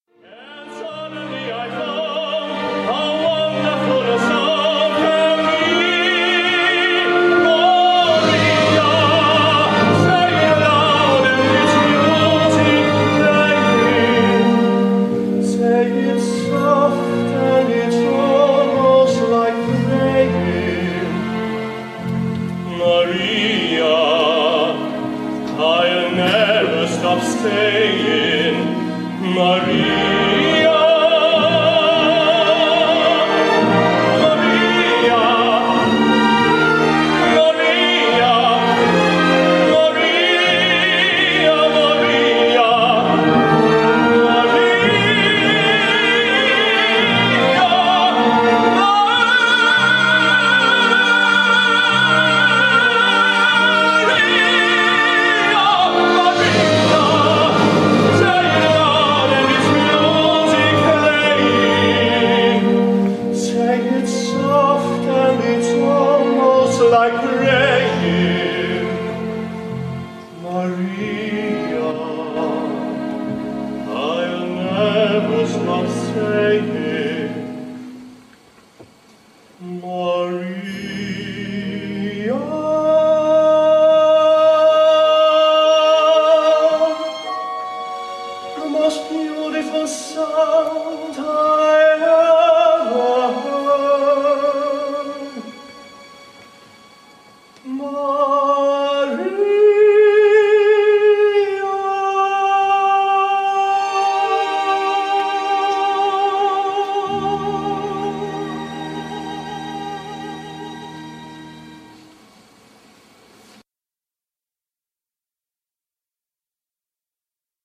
Tony
Komische Oper